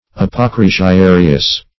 Meaning of apocrisiarius. apocrisiarius synonyms, pronunciation, spelling and more from Free Dictionary.
Search Result for " apocrisiarius" : The Collaborative International Dictionary of English v.0.48: Apocrisiary \Ap`o*cris"i*a*ry\, Apocrisiarius \Ap`o*cris`i*a"ri*us\, n. [L. apocrisiarius, apocrisarius, fr. Gr.